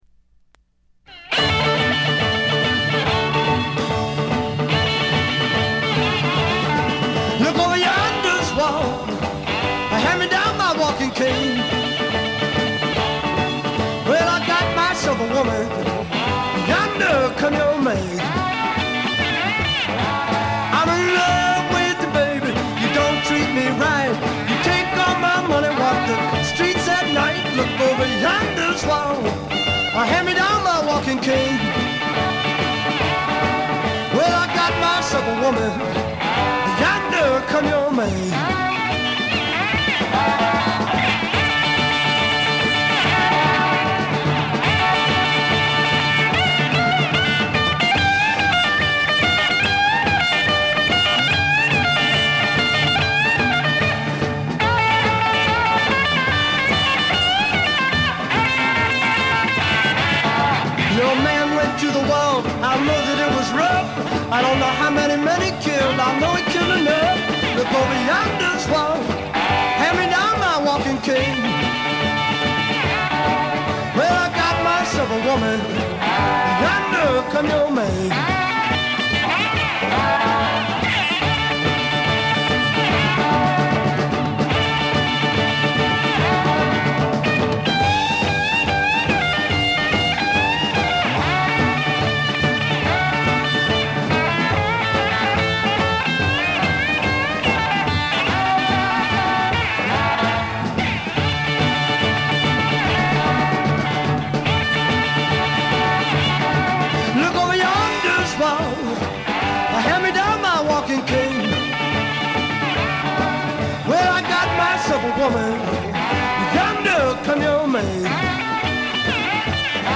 部分試聴ですが、わずかなノイズ感のみ。
暴風雨のような凄まじい演奏に圧倒されます。
試聴曲は現品からの取り込み音源です。
Harmonica